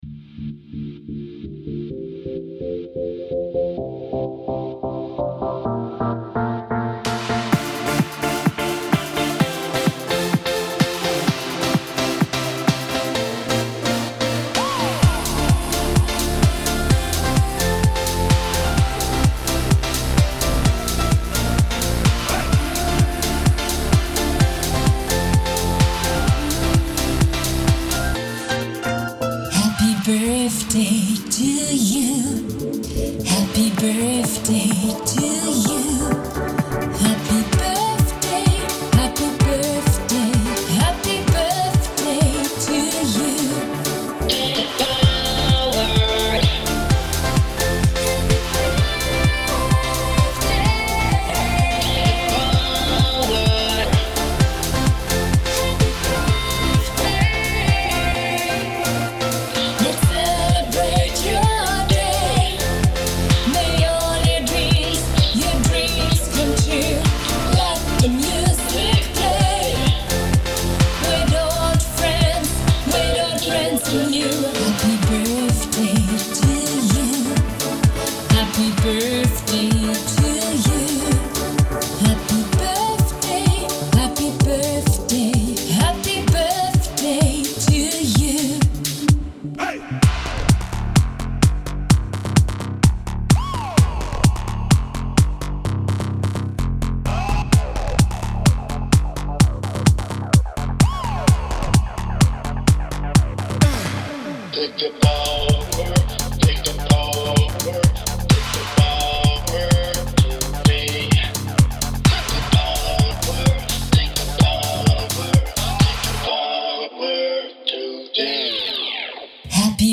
Ihre Partysängerin